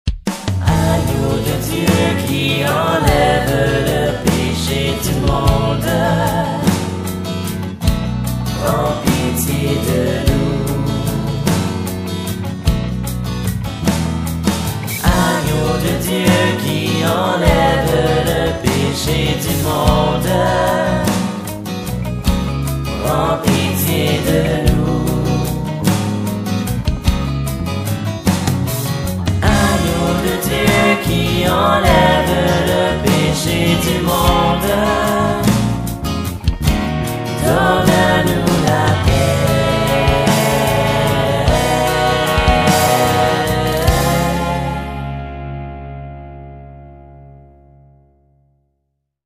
Chants divers
agneau_de_dieu_chant.mp3